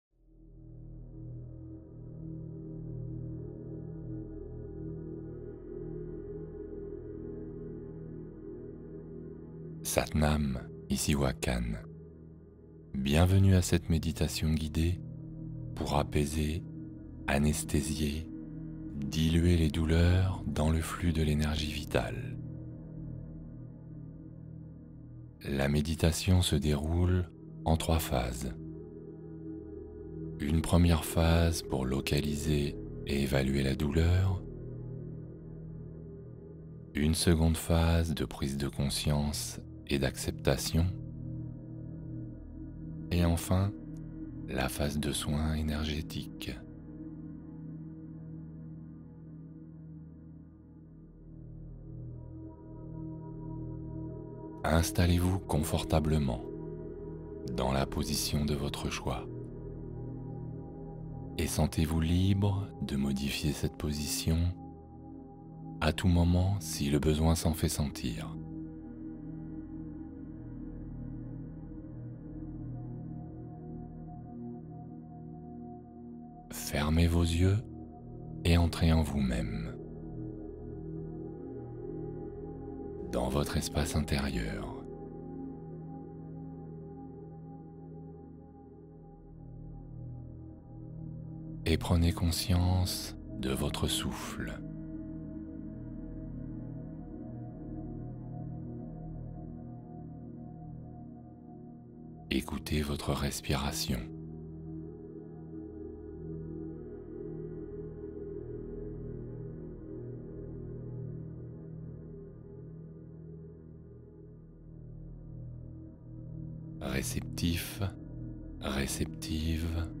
Méditation guidée pour accompagner l’angoisse du moment